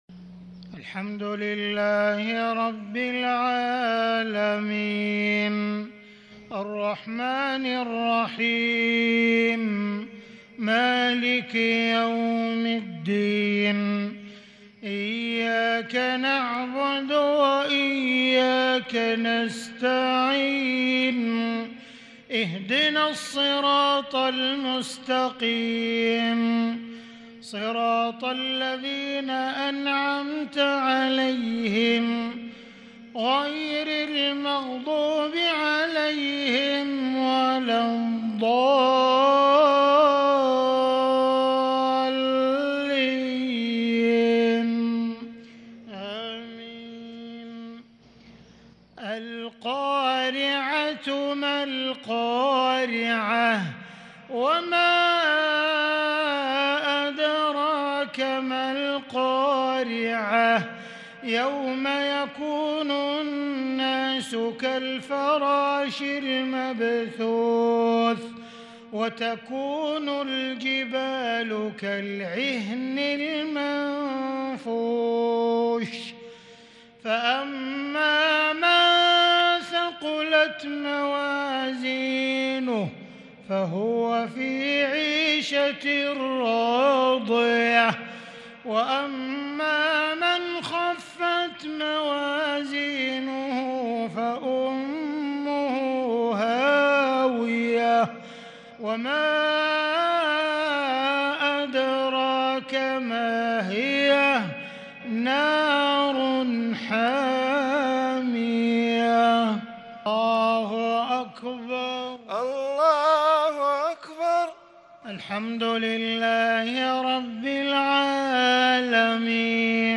صلاة المغرب 8-9-1442هـ سورتي القارعة و العصر | Maghrib prayer Surah Al-Qaari'a and Al-Asr 20/4/2021 > 1442 🕋 > الفروض - تلاوات الحرمين